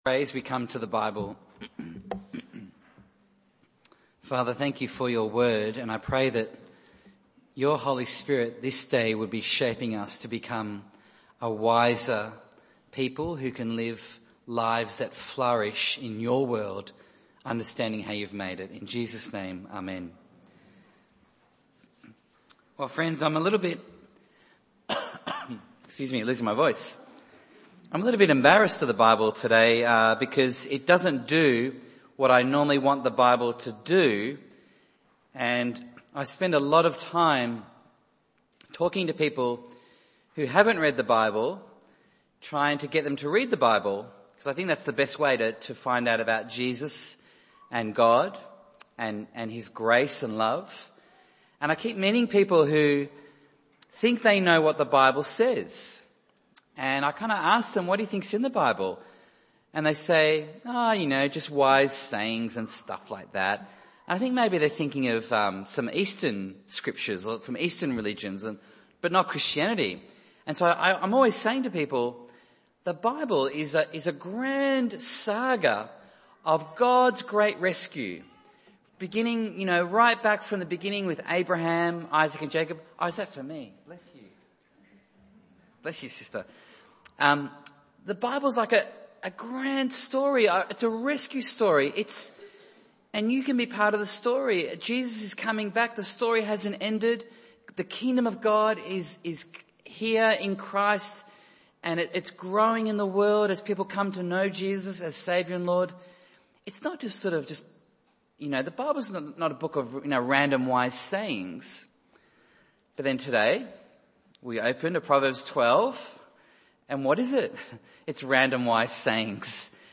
Bible Text: Proverbs 12:1-13 | Preacher: